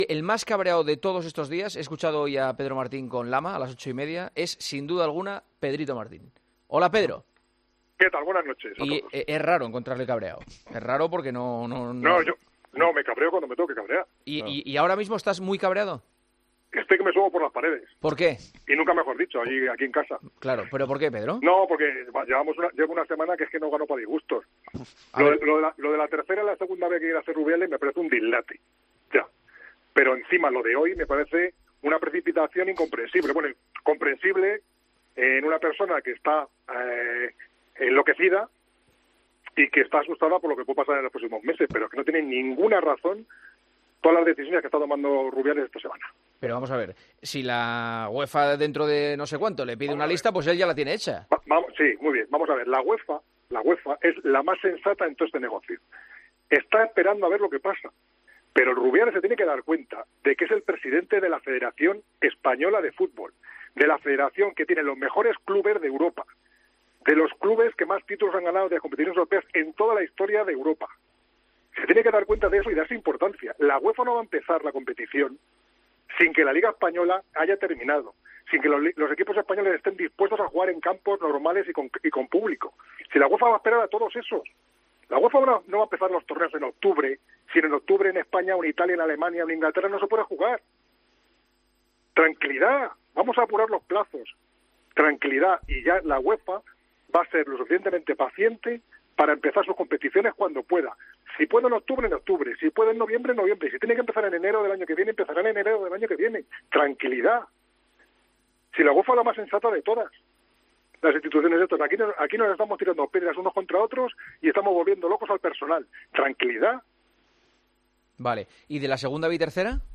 AUDIO: Debatimos en El Partidazo de COPE sobre la decisión de la Federación de aceptar la clasificación actual se se cancela LaLiga.